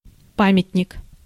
Ääntäminen
Ääntäminen US : IPA : [ˈmɑːn.jə.mənt]